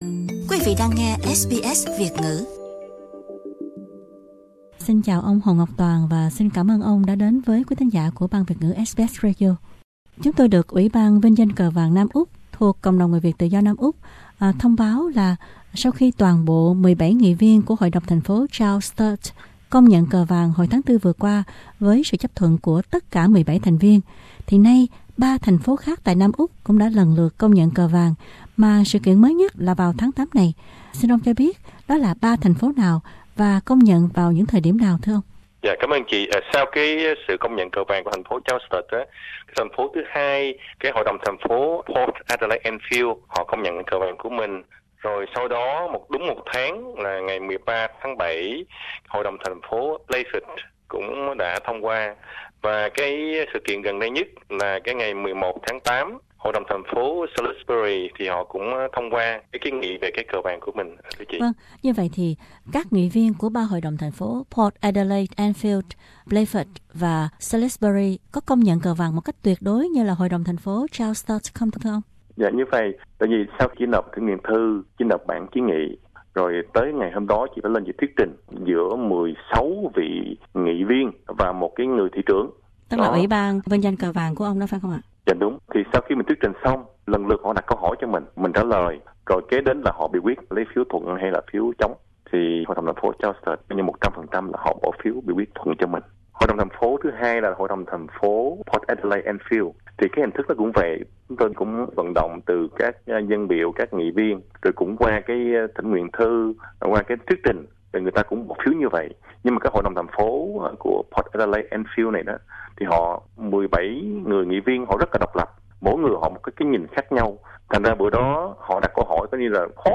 Cộng đồng Người Việt Tự do Nam Úc thông báo đã có thêm ba Hội đồng thành phố tại tiểu bang này vinh danh cờ vàng sau thành phố Charles Sturt. Như vậy hiện nay đã có tổng cộng bốn thành phố tại Nam Úc chính thức công nhận cờ vàng là biểu tượng của người Việt tự do. Mời quý vị cùng tìm hiểu về tin này qua phần phỏng vấn